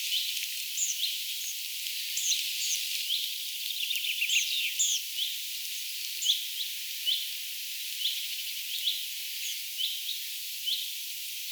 metsäkirvinen lentää, ääntelyä
metsakivinen_lentaa_ja_aantelee.mp3